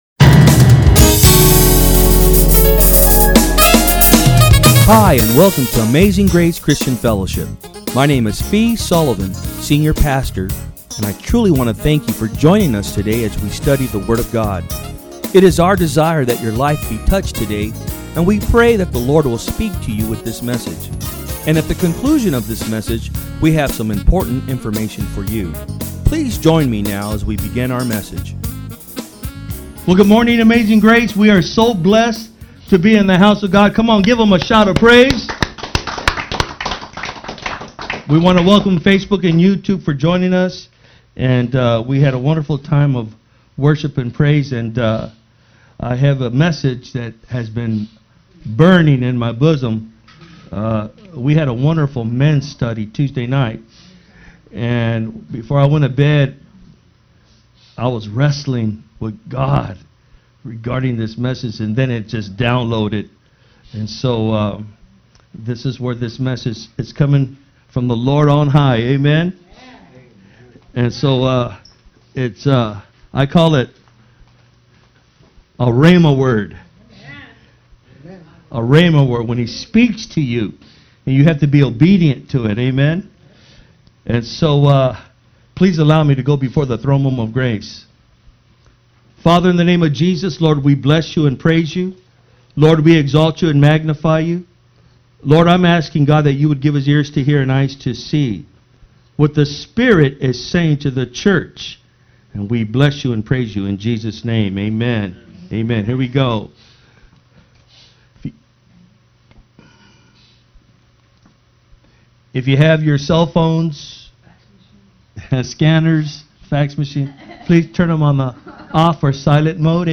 Sermons
From Service: "Sunday Am"